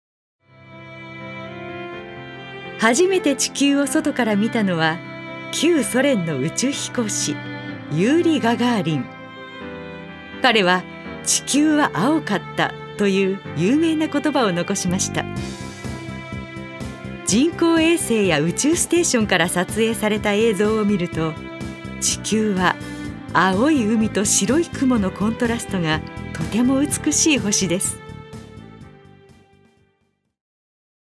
女性タレント
音声サンプル
ナレーション３